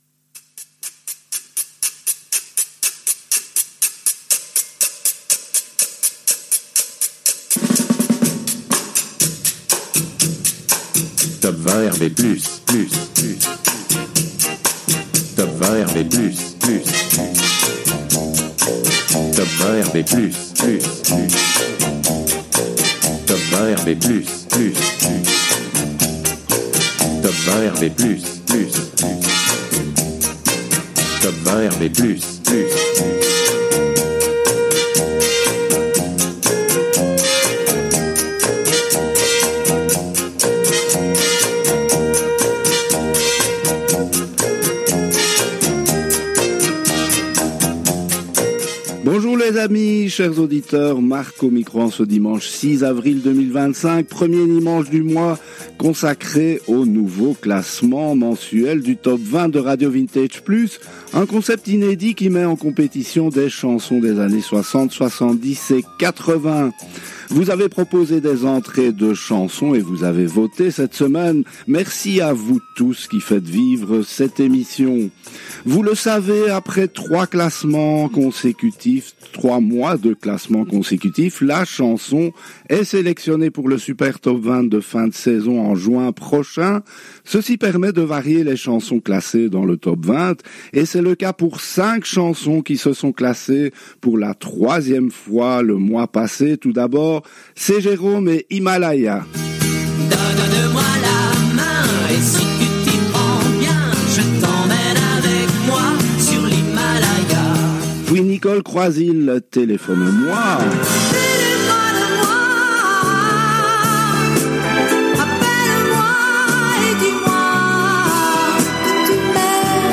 diffusé en direct le dimanche 06 avril 2025 à 10h en direct des studios belges de RADIO RV+